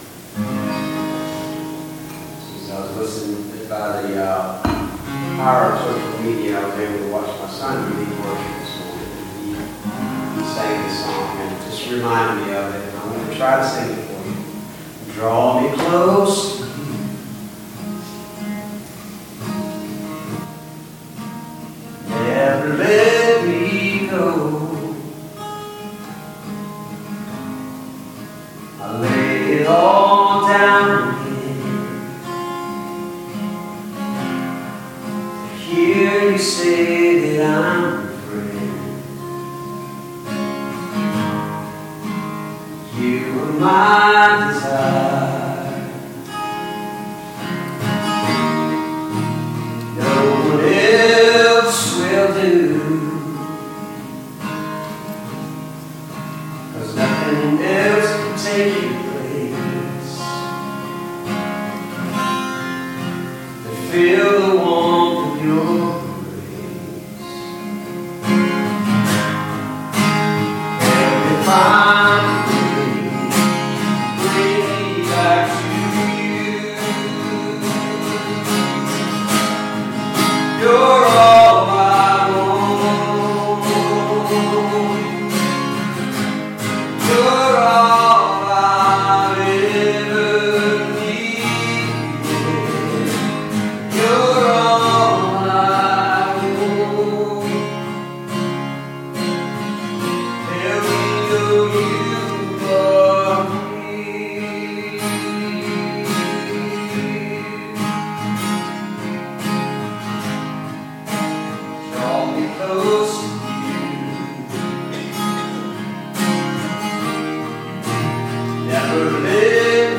2022 Bethel Covid Time Service
Special Music